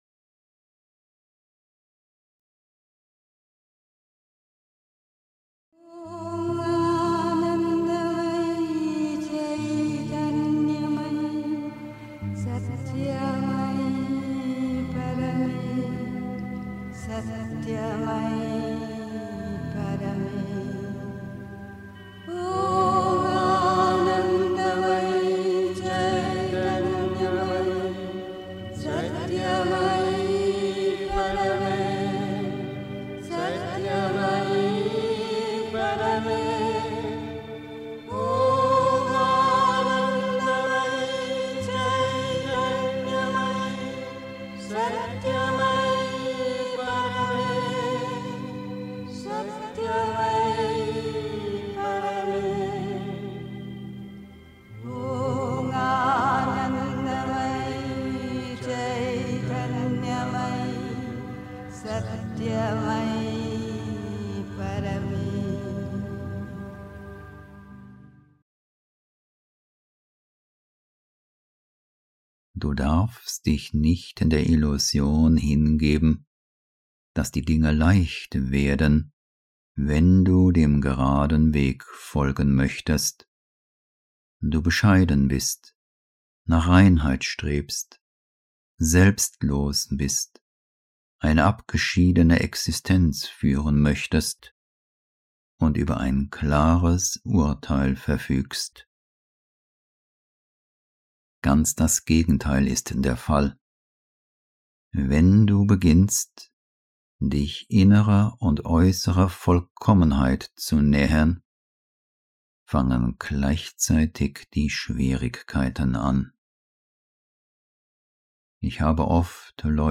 1. Einstimmung mit Musik. 2. Sei gut um des Gutseins willen (Die Mutter, The Sunlit Path) 3. Zwölf Minuten Stille.